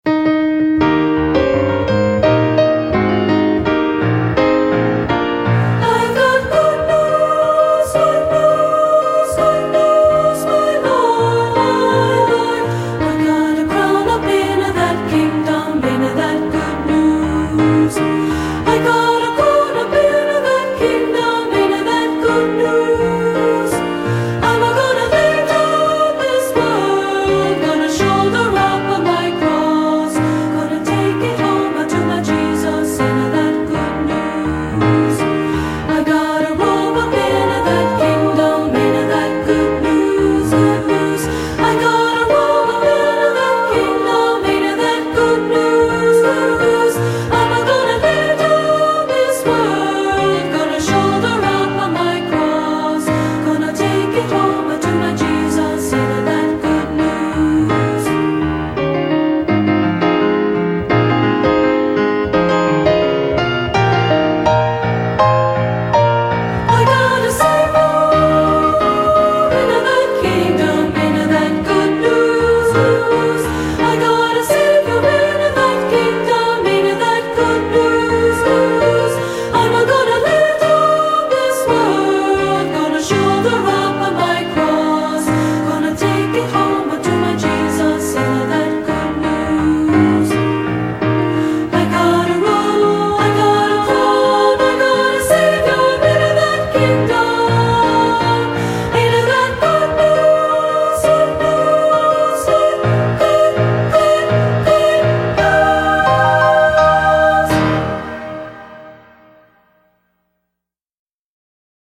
Composer: African American Spiritual
Voicing: Unison|2-Part